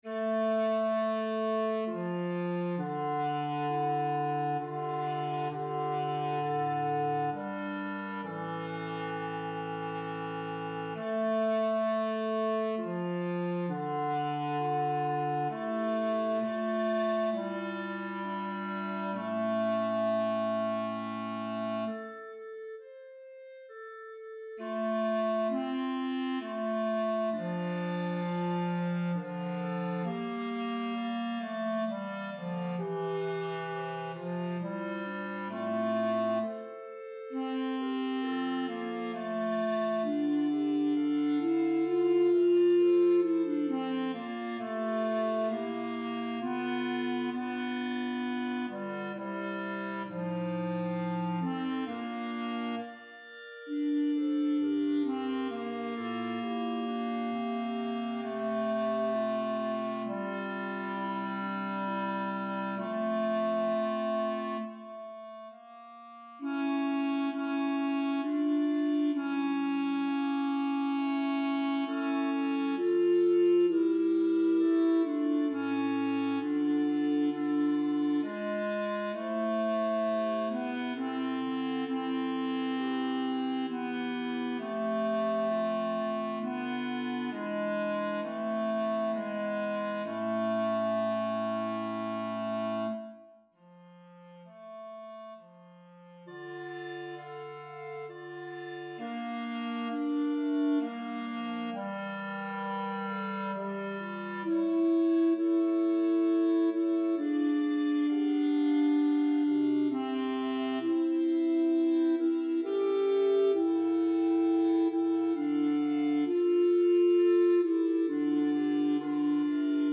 1_Kyrie_op_83_RR_Tenor.mp3